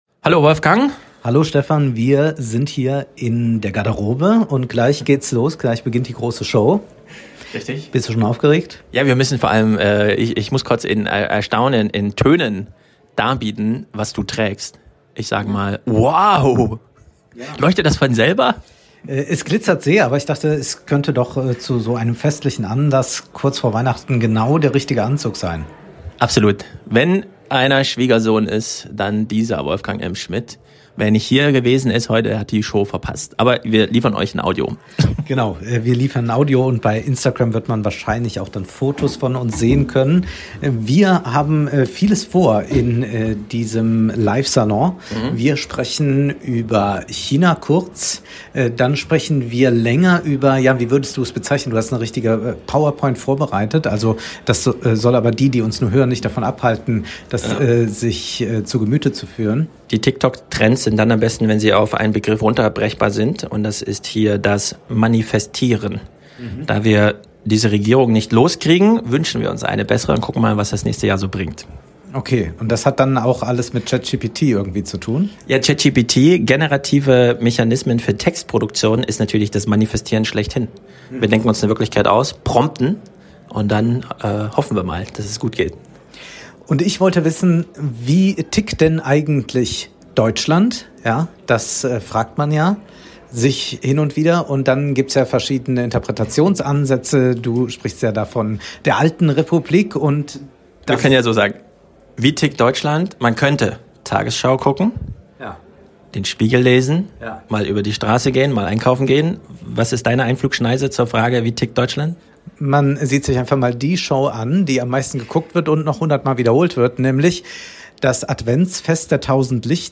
Live in Frankfurt: Chinas Jugend, Manifestieren, OpenAI